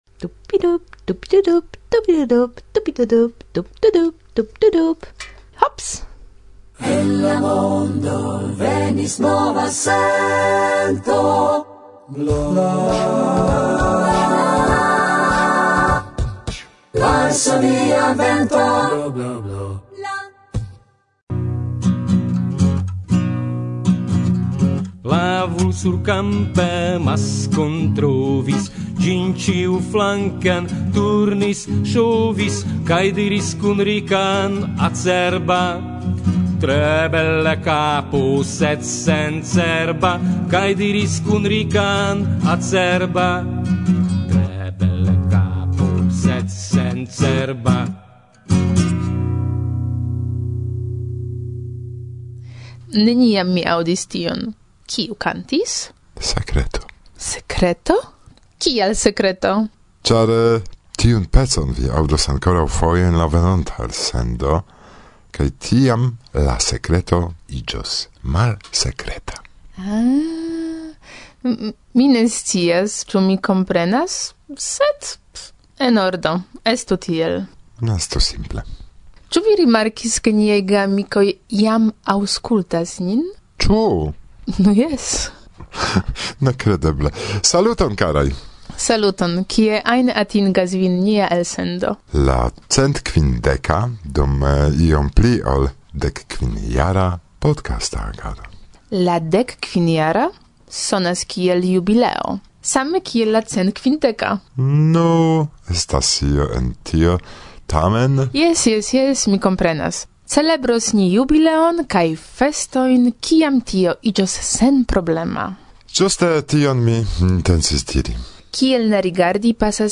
En la varsovia provizora studio enkonduke